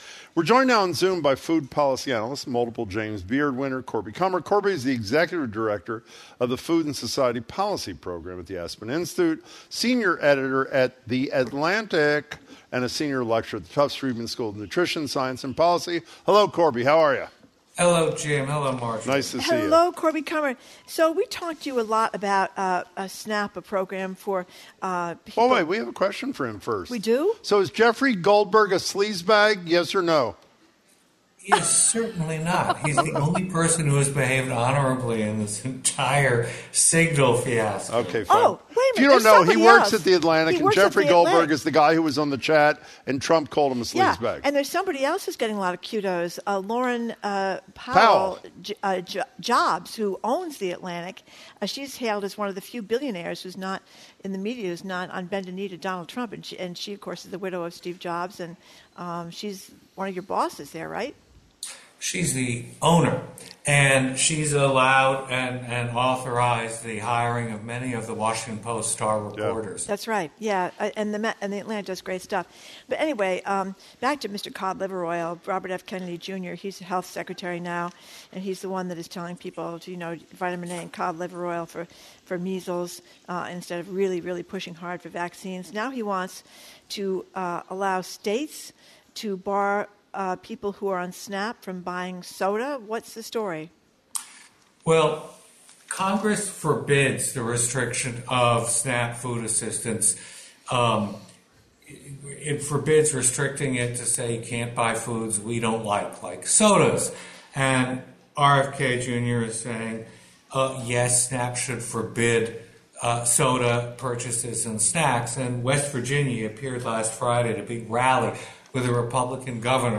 In this discussion